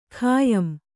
♪ khāyam